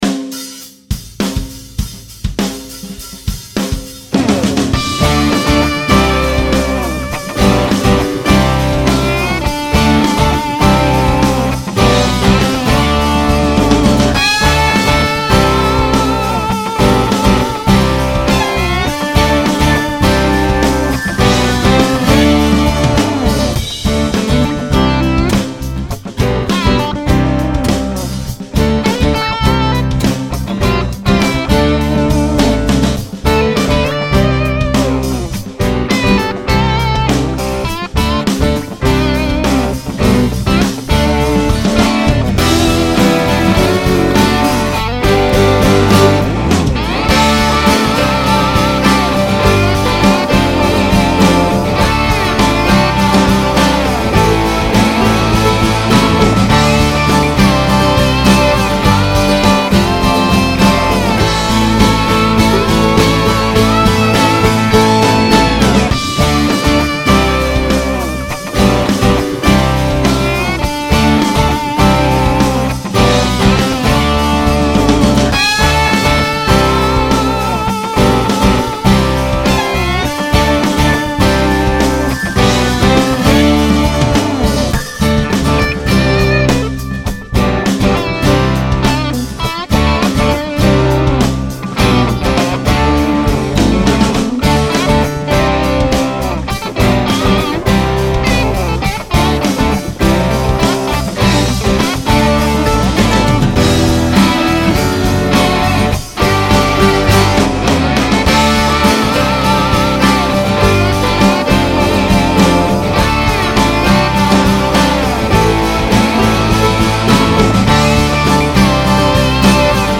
EstiloSoul